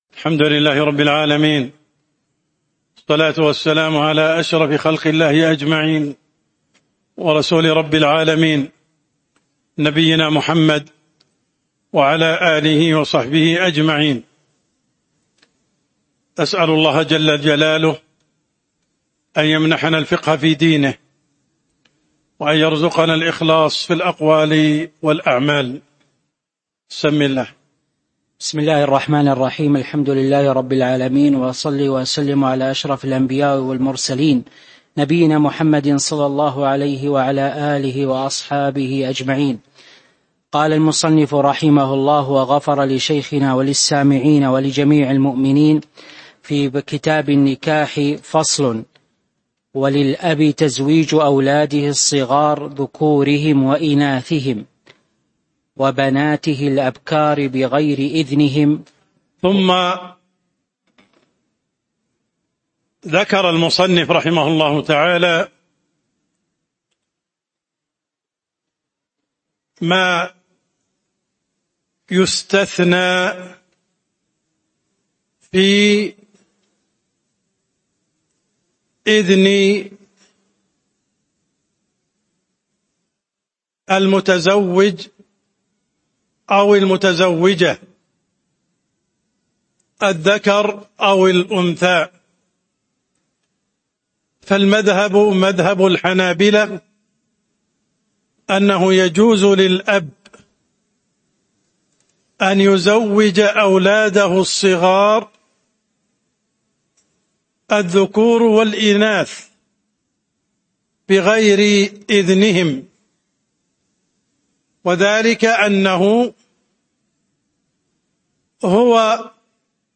تاريخ النشر ٢٤ شوال ١٤٤٦ هـ المكان: المسجد النبوي الشيخ: عبدالرحمن السند عبدالرحمن السند فصل في الاستئذان في التزويج (02)